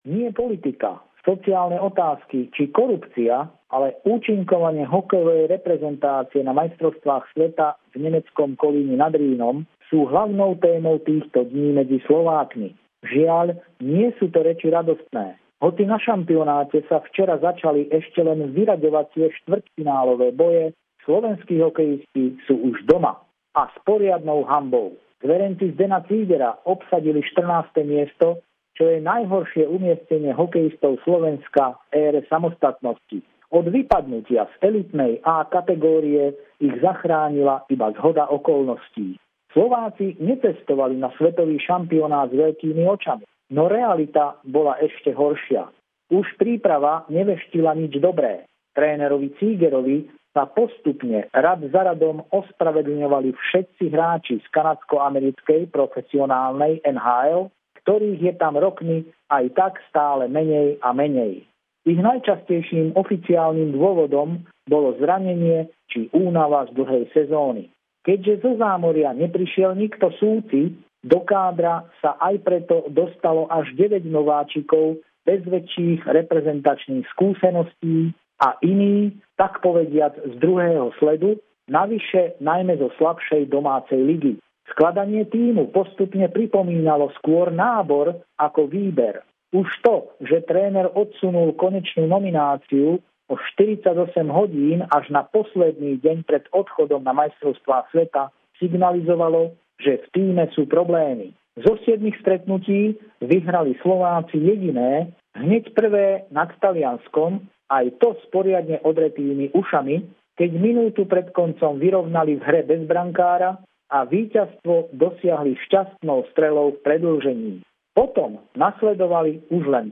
Titulok hovorí za všetko - čo predchádzalo a predznamenalo pád nášho hokeja. Pravidelný telefonát týždňa